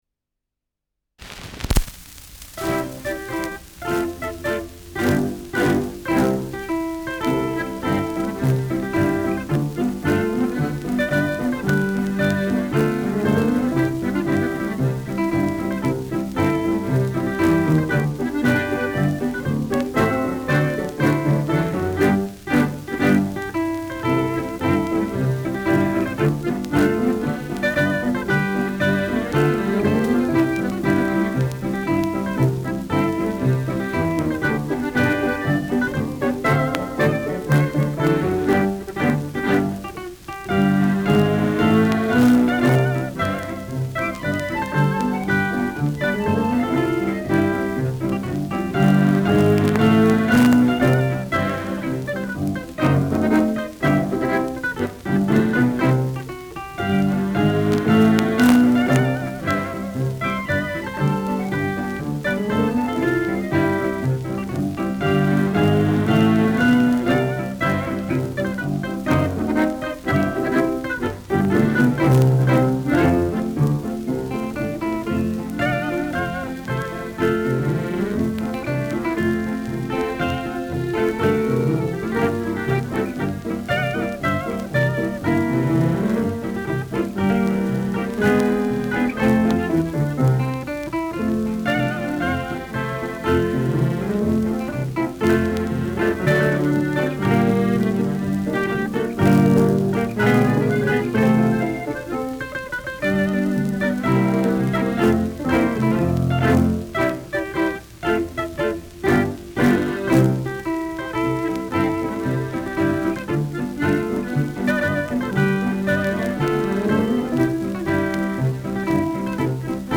Schellackplatte
Humoristischer Vortrag* FVS-00003